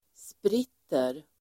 Uttal: [spr'it:er]